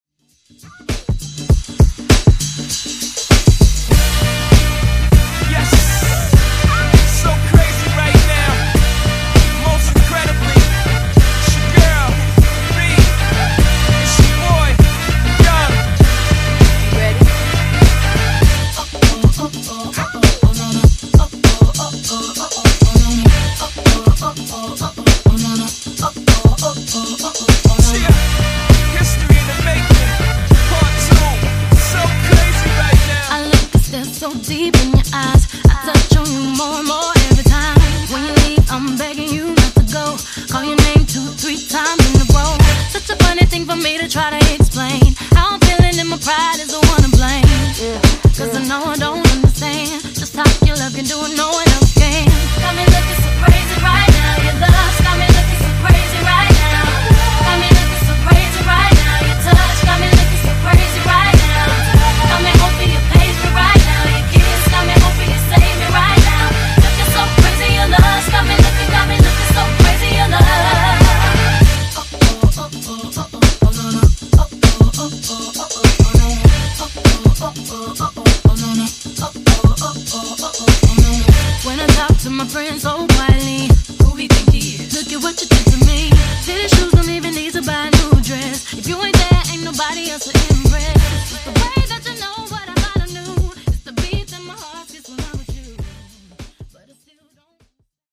Genres: RE-DRUM , REGGAETON Version: Clean BPM: 100 Time